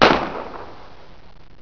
knaller2.wav